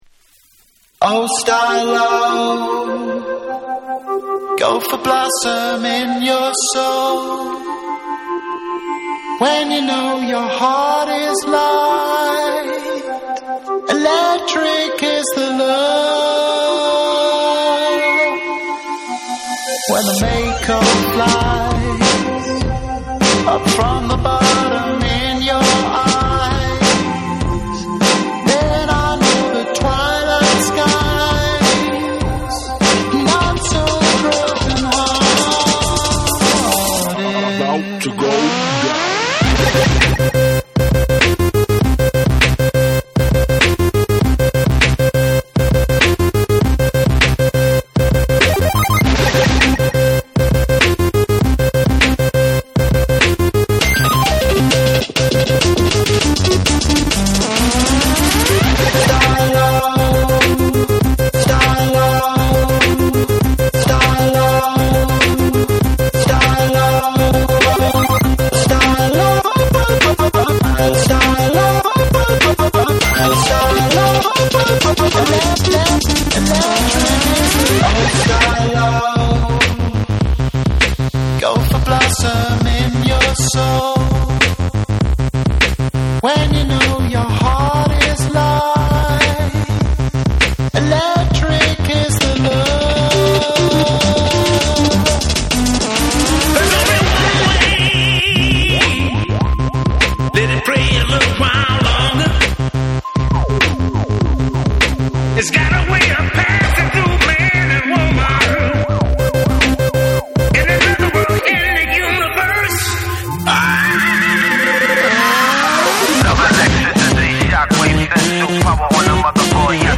DJフレンドリーなリミックス・ヴァージョンを搭載。